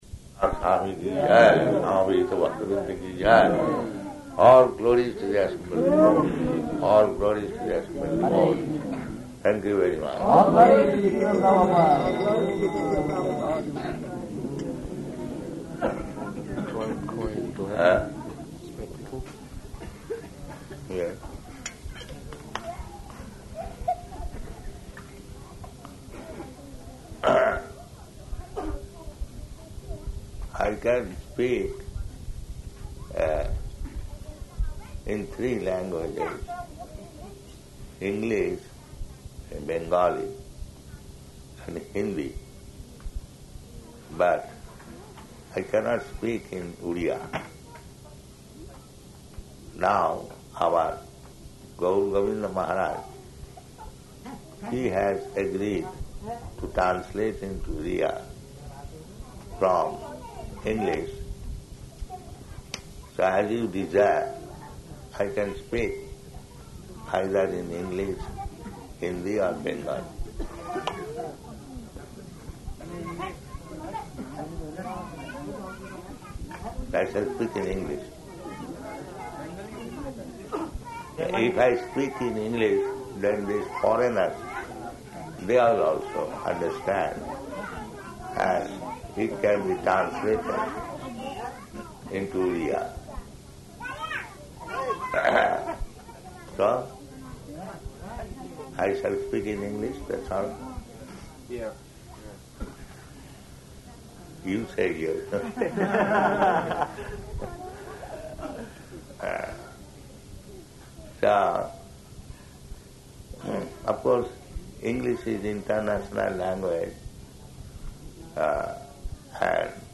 Evening Lecture
Evening Lecture --:-- --:-- Type: Lectures and Addresses Dated: January 19th 1977 Location: Bhubaneswar Audio file: 770119LE.BHU.mp3 Prabhupāda: [ prema-dhvani prayers ] All glories to the assembled devotees.